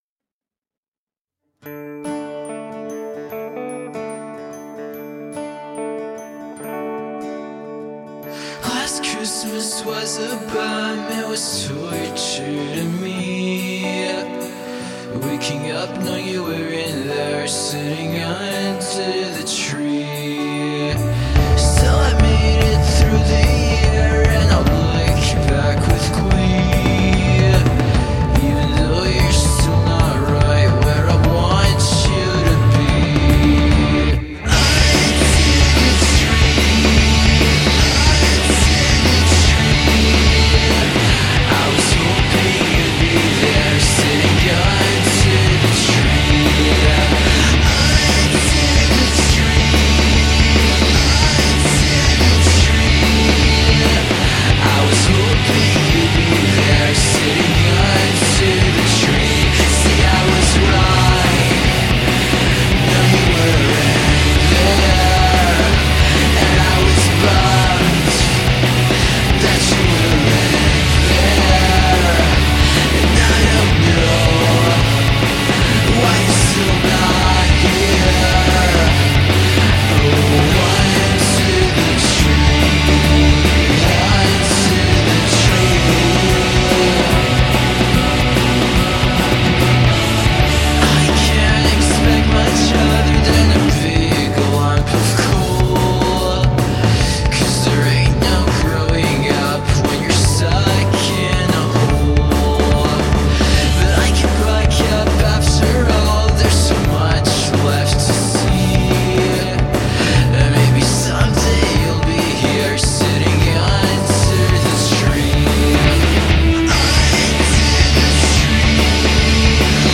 alternative rock
Christmas song